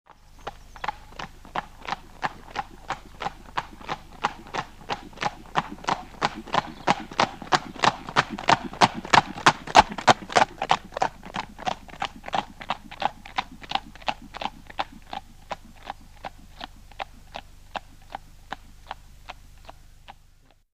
На этой странице собраны натуральные звуки телег и повозок: скрип деревянных колес, цоканье копыт лошадей, шум движения по грунтовой дороге или брусчатке.
Звук лошади рысцой приближается и удаляется